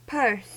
1. ^ "Peirce", in the case of C. S. Peirce, always rhymes with the English-language word "terse" and so, in most dialects, is pronounced exactly like the English-language word "
En-us-purse.ogg.mp3